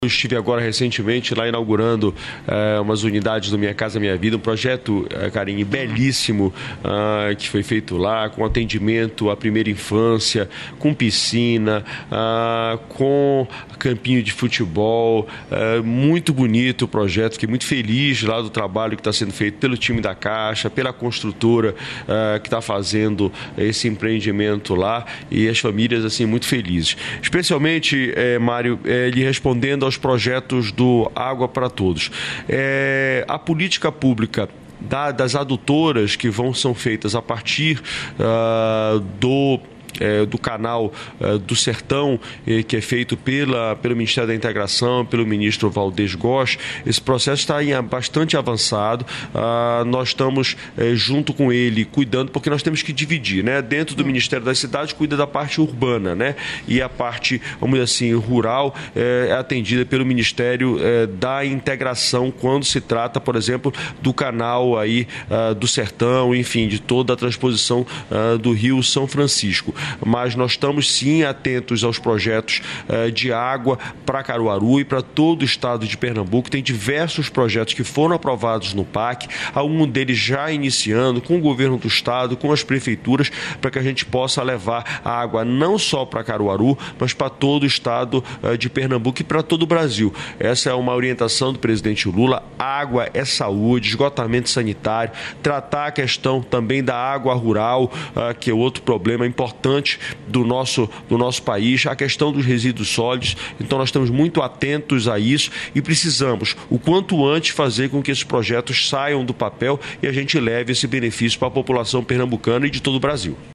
Trecho da participação do ministro das Cidades, Jader Filho, no programa "Bom Dia, Ministro" desta quarta-feira (12), nos estúdios da EBC no Encontro de Novos Prefeitos e Prefeitas, em Brasília.